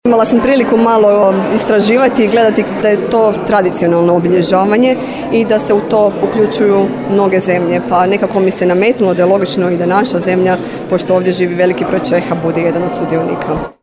Uoči početka koncerta otvorena je izložba dječijih radova nastalih na glazbeno-likovnoj radionici „Slikajmo glazbu“ koju je otvorio saborski zastupnik Vladimir Bilek. Inicijatorica ovog projekta je zamjenica župana Tanja Novotni Golubić: